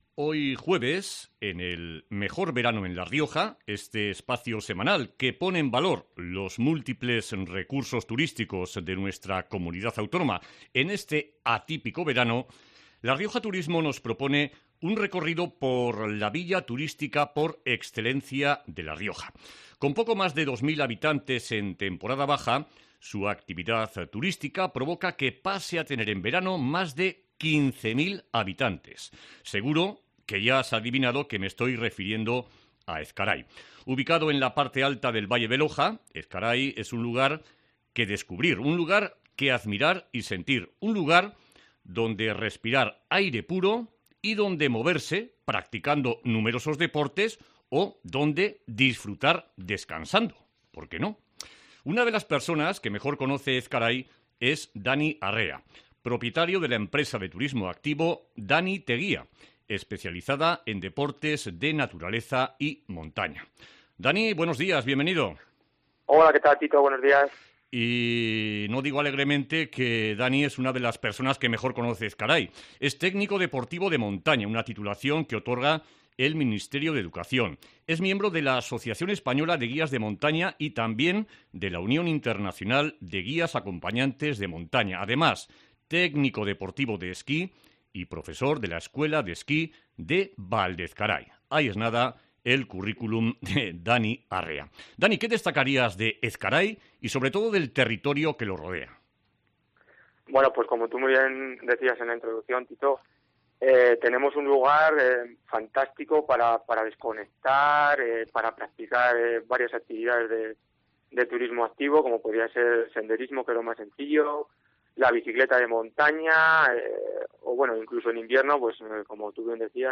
Entrevista al guía de montaña